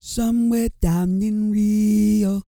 E-CROON 3010.wav